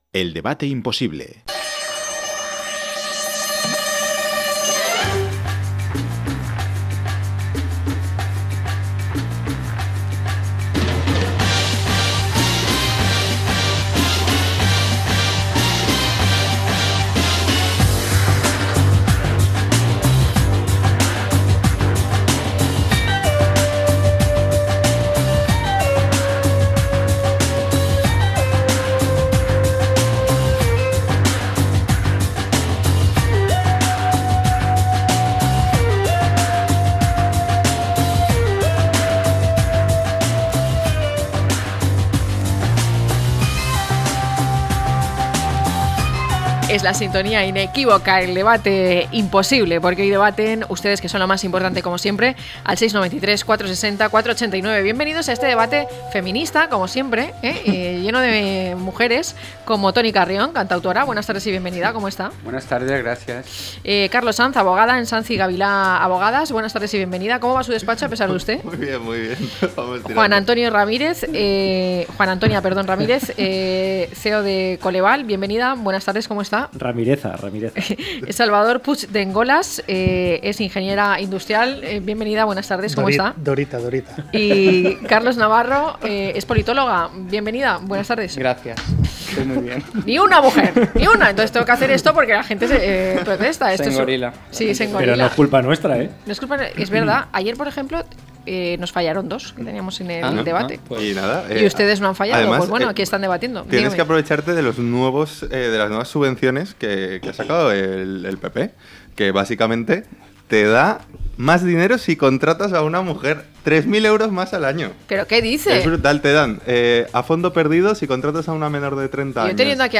¿Los jóvenes españoles cada vez más adictos?, a debate - La tarde con Marina